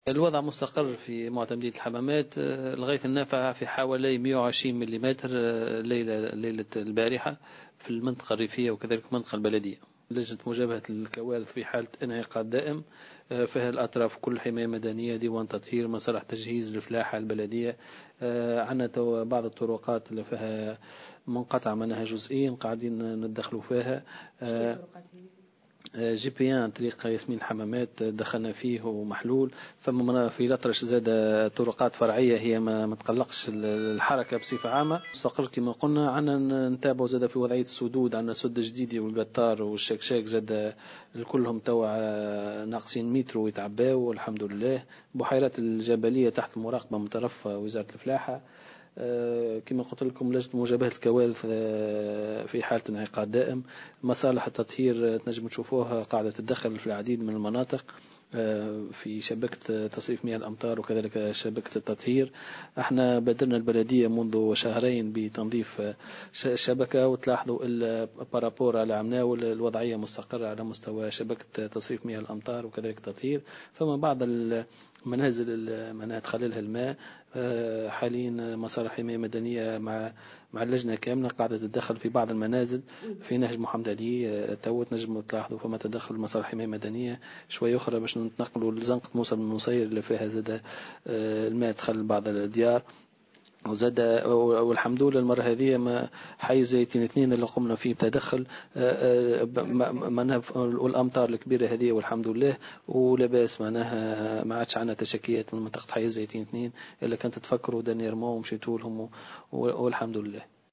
أكد معتمد الحمامات، حسام الصغير في تصريح لمراسلة "الجوهرة أف أم" بالجهة أنه تم التدخل من أجل إعادة فتح بعض الطرقات التي تعطلت بسبب هطول الأمطار، مشيرا إلى أن لجنة مجابهة الكوارث في حالة انعقاد دائم بحضور مختلف الأطراف من حماية مدنية وممثلين عن وزارة الفلاحة وديوان التطهير ومصالح التجهيز.